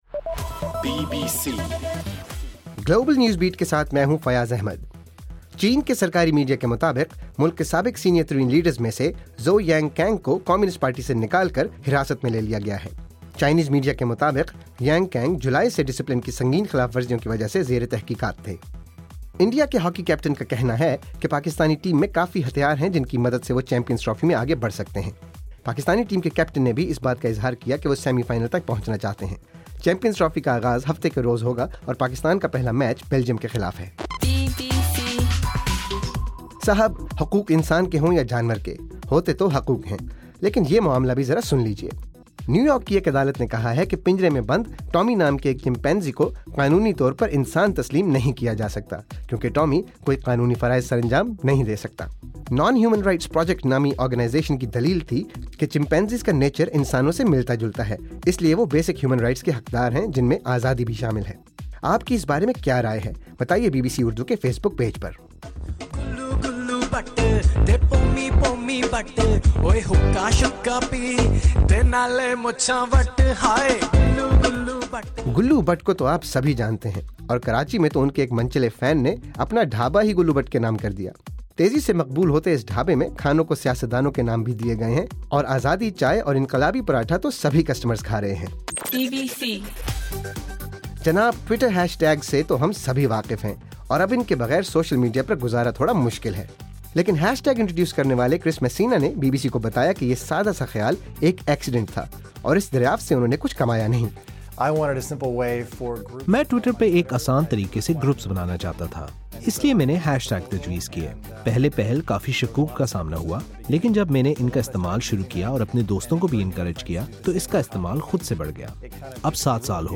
دسمبر 5: رات 12 بجے کا گلوبل نیوز بیٹ بُلیٹن